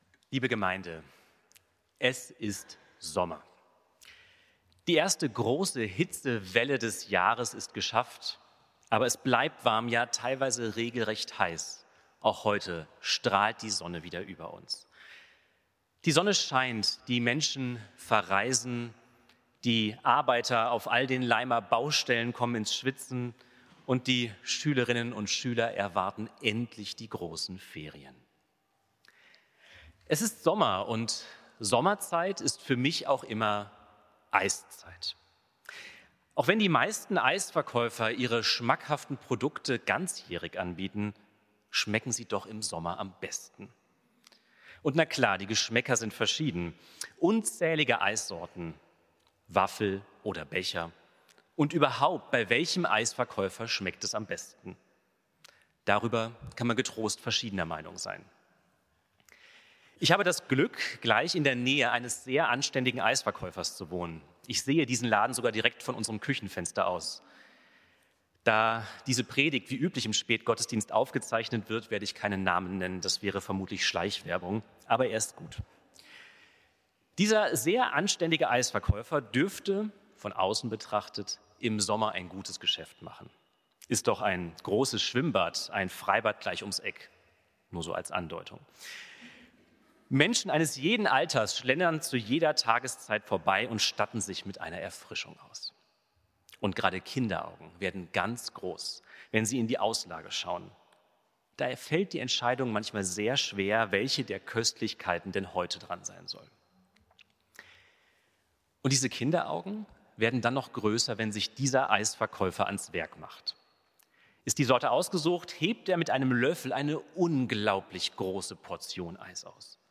Gottesdienste – Paul-Gerhardt-Kirche